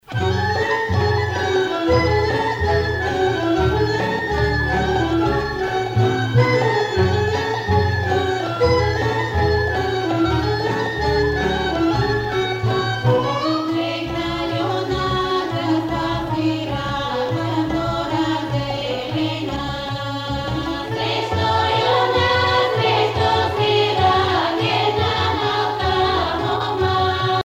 Pièce musicale orchestrée n°14 avec choeur
Localisation Bulgarie
Pièce musicale inédite